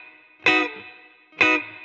DD_TeleChop_130-Bmaj.wav